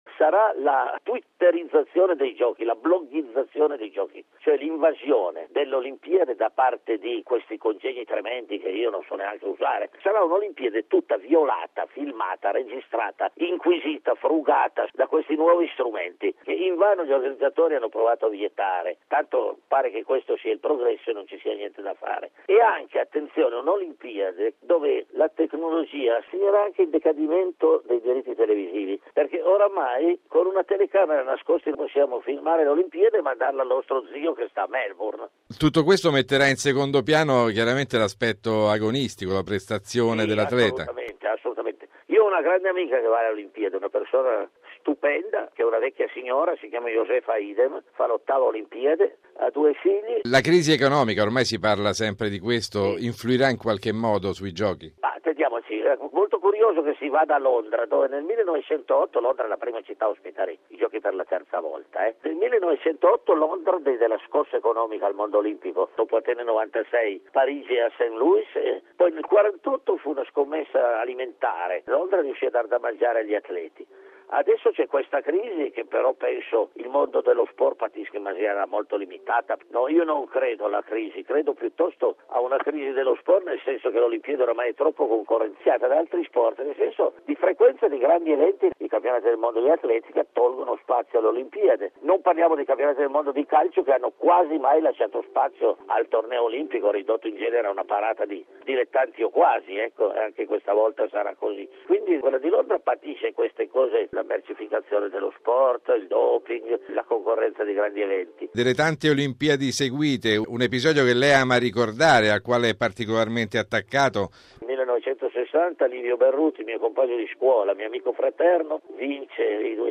noto giornalista sportivo